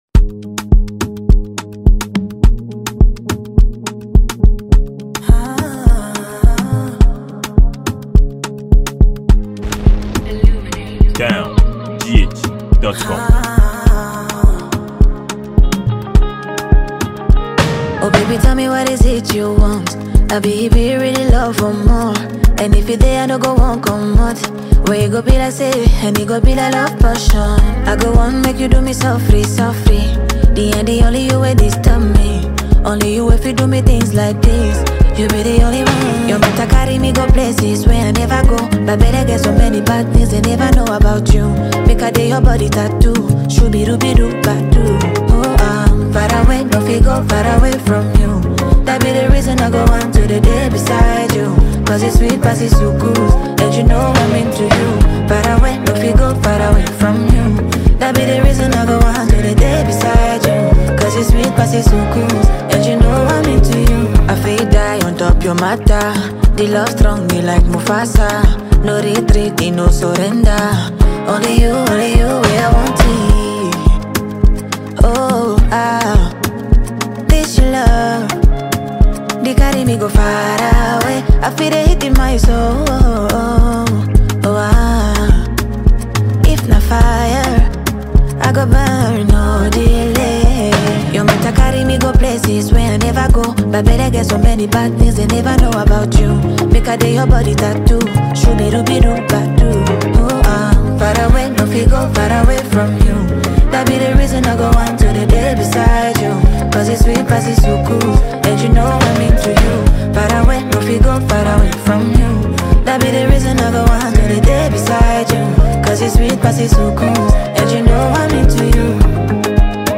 Young talented Ghanaian afrobeat singer
a free mp3 love contemporary tune for download